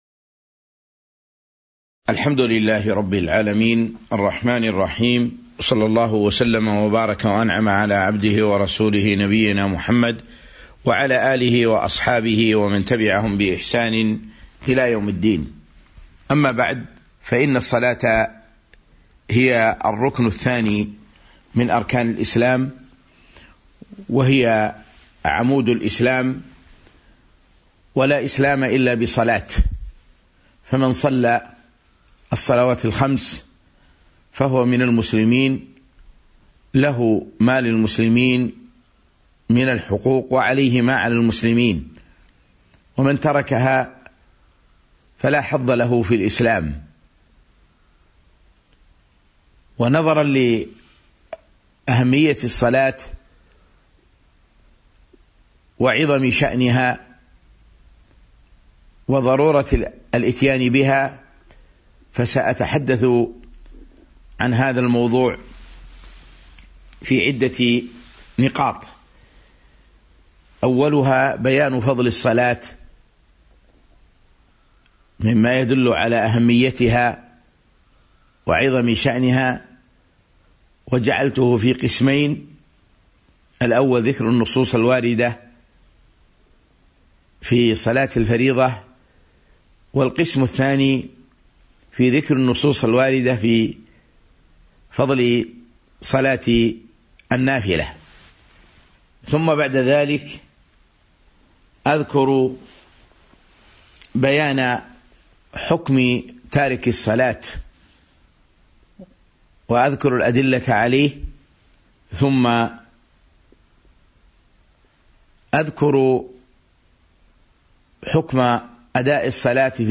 تاريخ النشر ١٢ جمادى الأولى ١٤٤٢ هـ المكان: المسجد النبوي الشيخ